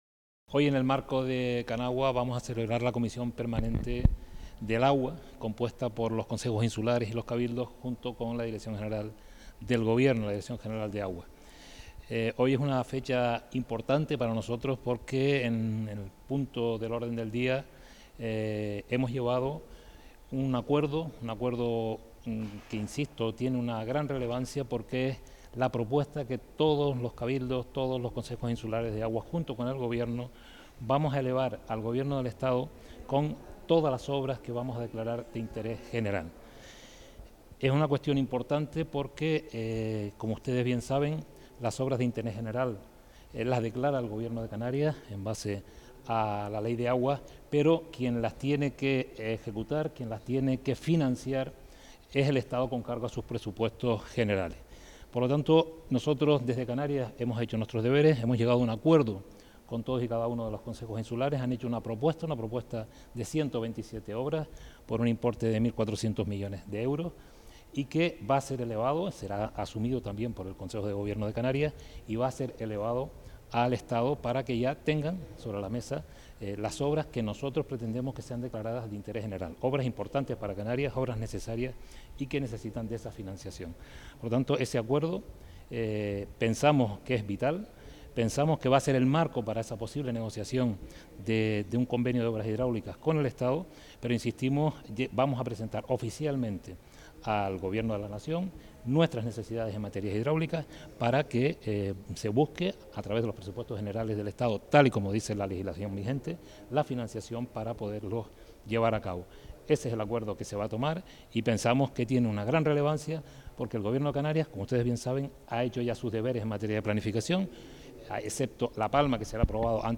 Entrevistas y declaraciones en Fuerteventura Digital
Manuel Miranda, consejero de Política Territorial, sobre las inversiones hidráulicas que necesita Canarias para los siete consejos insulares de aguas de Canaria